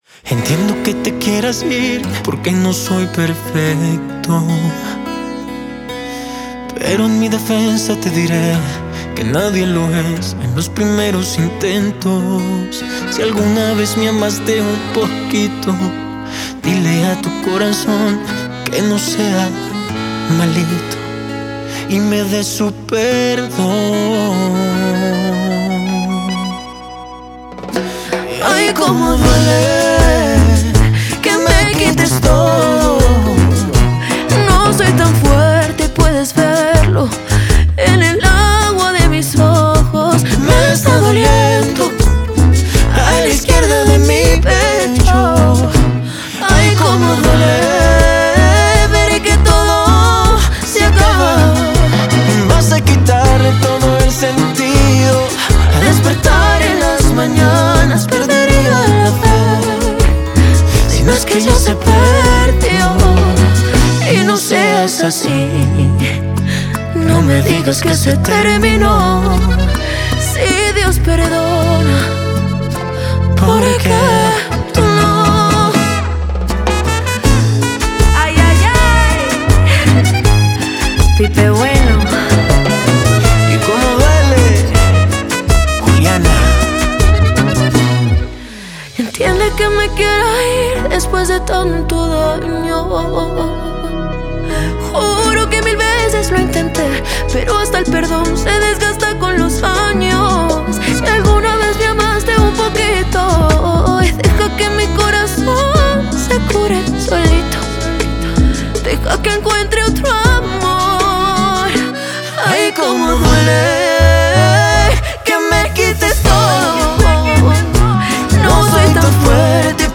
un sonido más pop